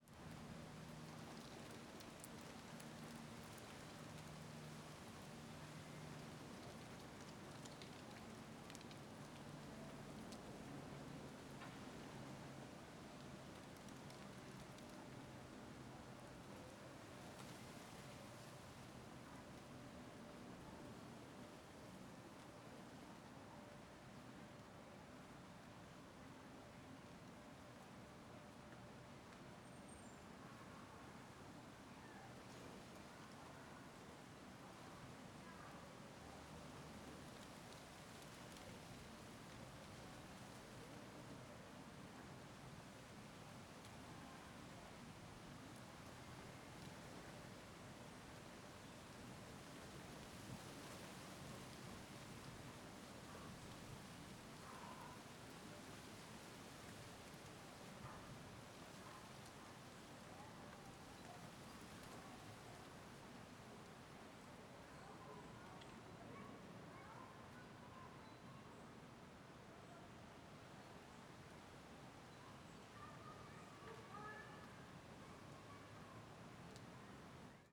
Ambiente residencial tranquilo dia vozes longes Ambiente externo , Crianças , Dia , Residencial tranquilo , Rumble cidade , Vento , Voz feminia Brasília Stereo
CSC-04-130-OL- Ambiente residencial tranquilo dia vozes longes .wav